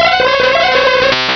pokeemerald / sound / direct_sound_samples / cries / hypno.aif